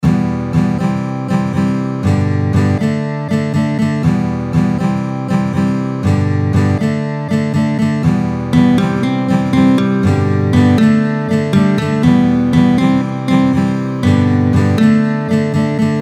démo guitare